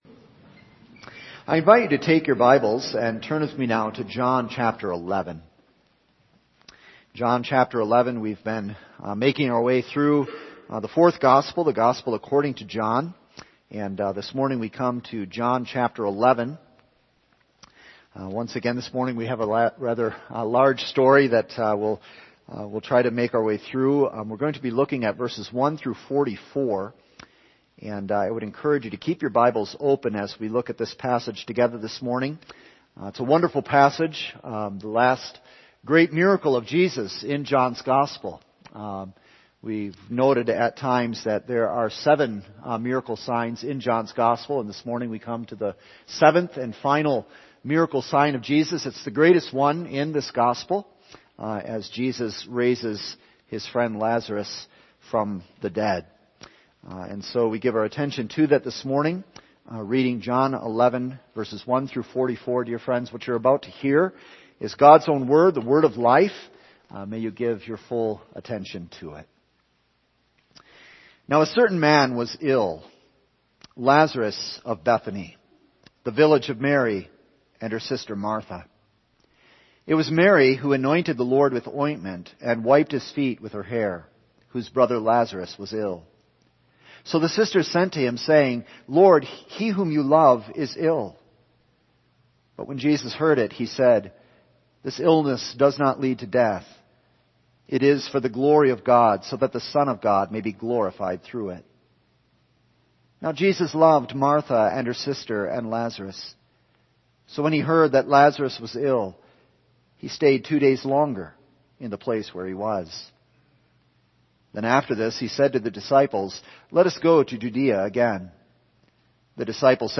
All Sermons Jesus Summons Death January 30